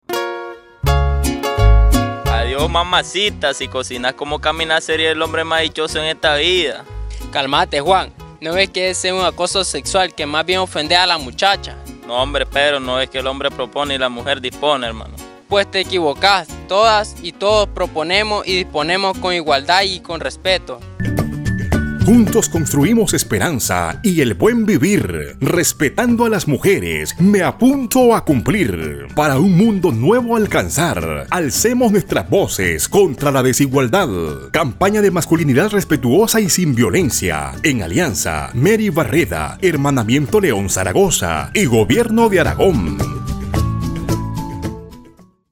Importante destacar la participación protagónica de hombres de las comunidades del sector rural noreste de León.